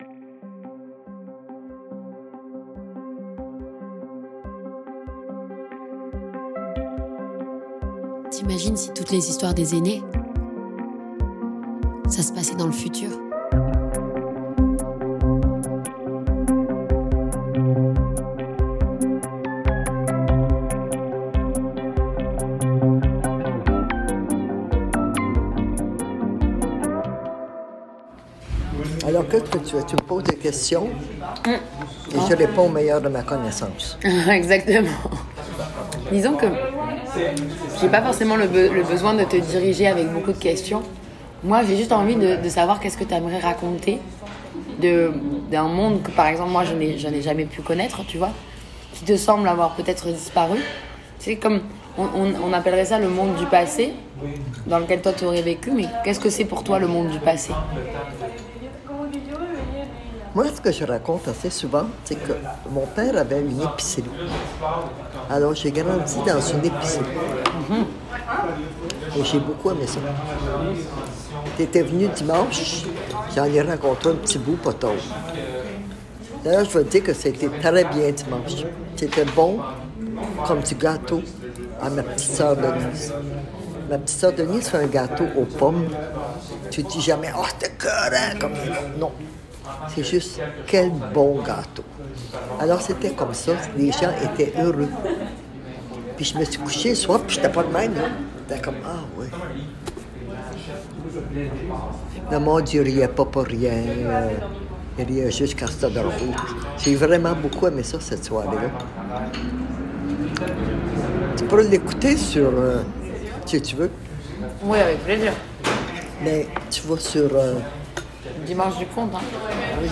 Type Entretien
Nous sommes dans le café de la Grande Bibliothèque de Montréal